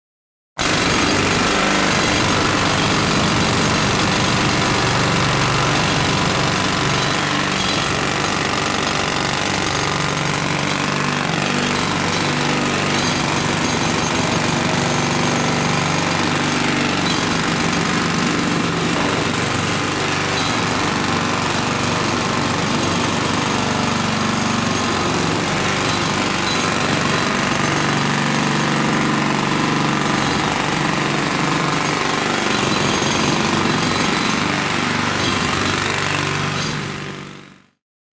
Звуки отбойного молотка
Громкий шум нескольких отбойных молотков